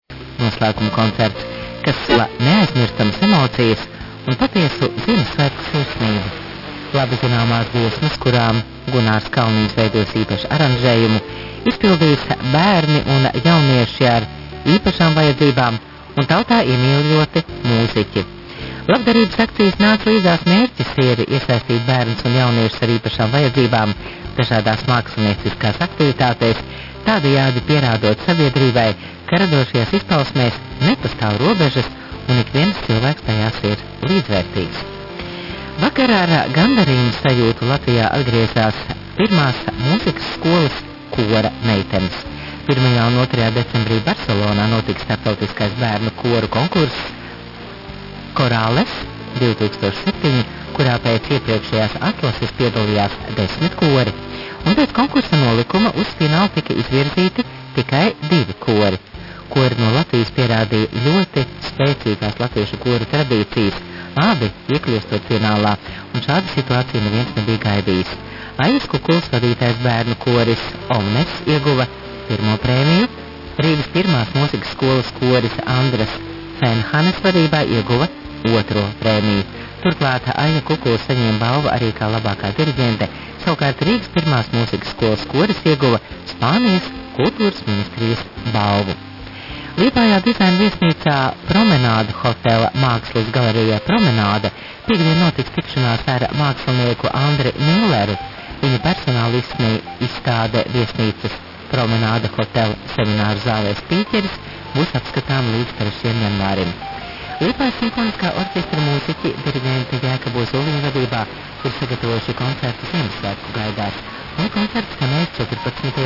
registrazione si sente in effetti l'annuncio di un evento al Promenade Hotel, un albergo di Liepaja.